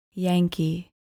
Pronounced: YANG-kee